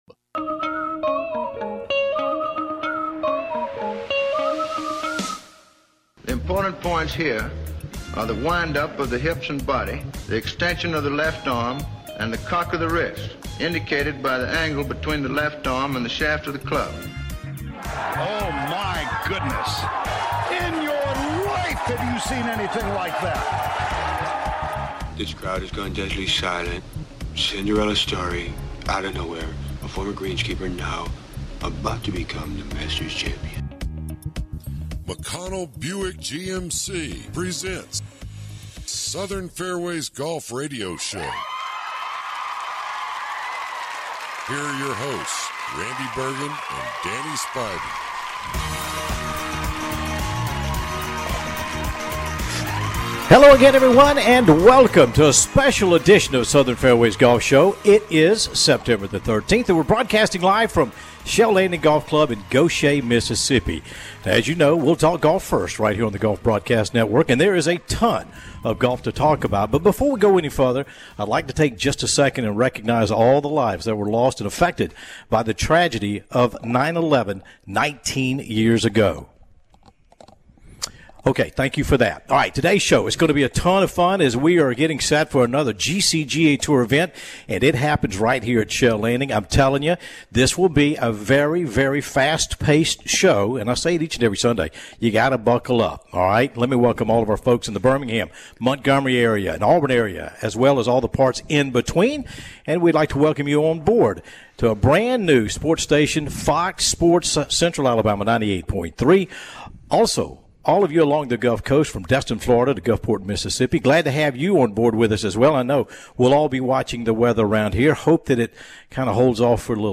broadcasting from Fallen Oak